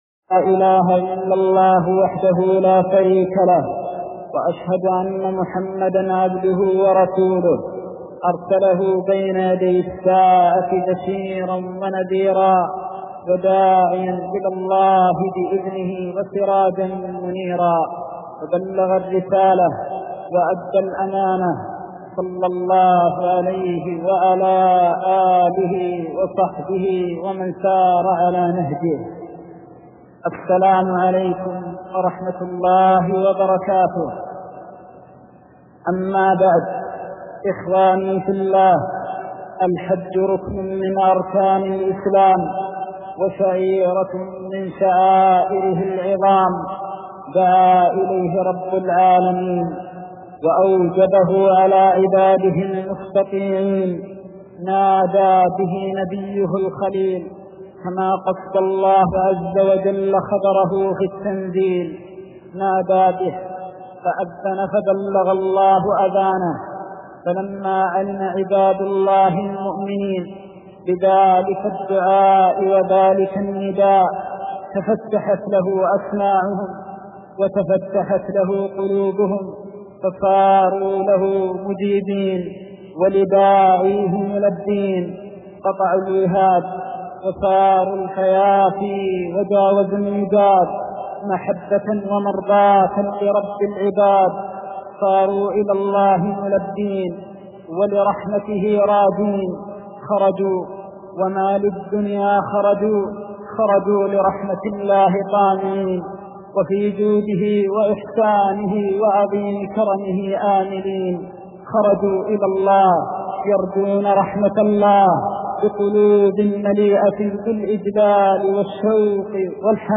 دمع في الحج كاملة جدا موثر وبكاء شديد